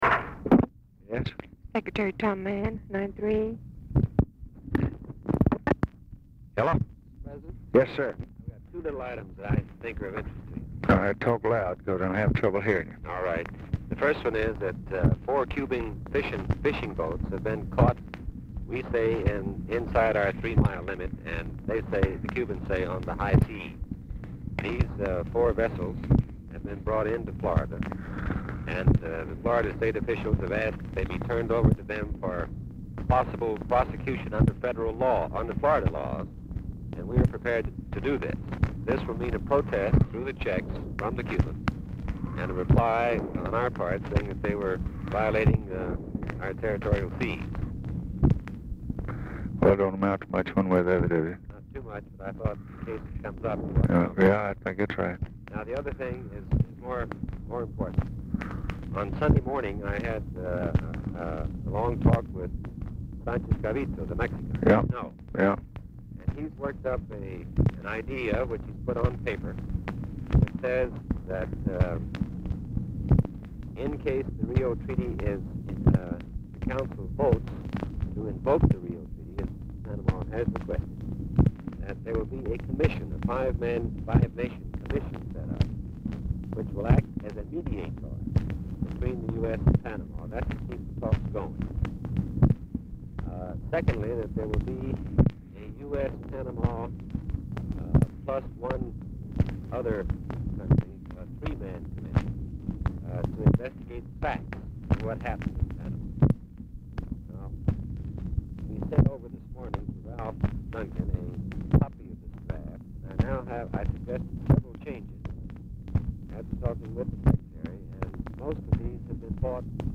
Telephone conversation # 1855, sound recording, LBJ and THOMAS MANN, 2/3/1964, 7:10PM
Format Dictation belt
Location Of Speaker 1 Oval Office or unknown location